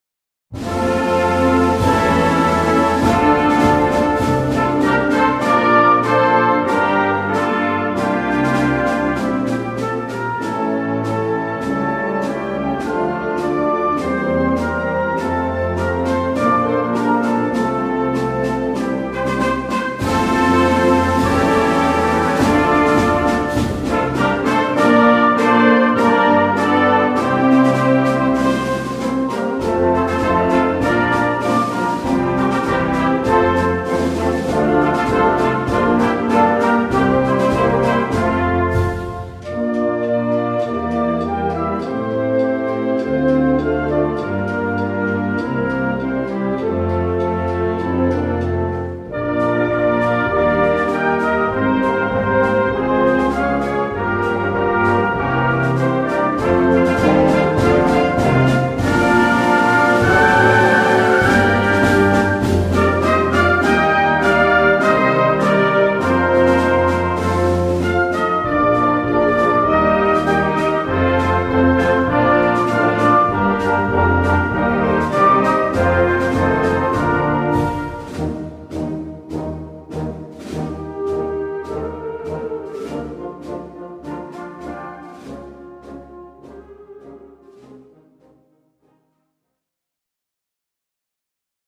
Répertoire pour Harmonie/fanfare - Défilé et parade